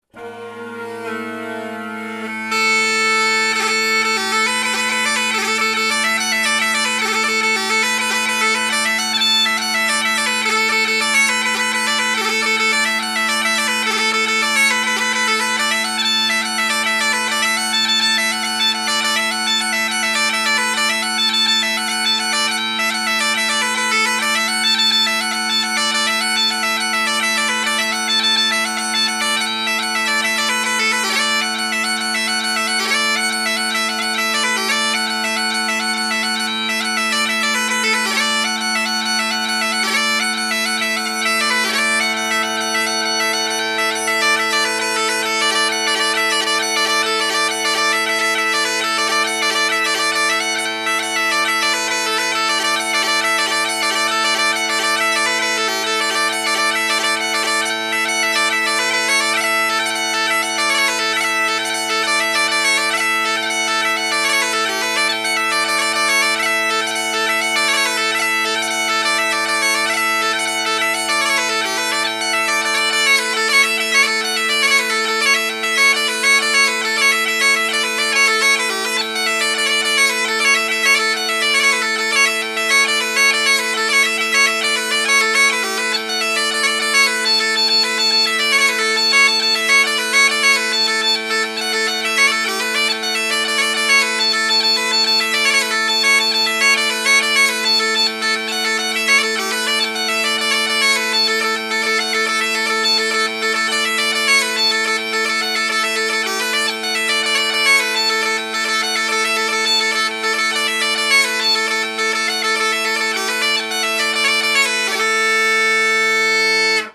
McCallum A440 Highland Pipe Chanter Review
Here is some audio of my Kron drones with 440 Overtone (Ackland) reeds and McCallum 440 chanter with John Elliott reed. The Zoom H4n is in front of me.
Lark in the Morning & King of the Pipers – Irish jigs!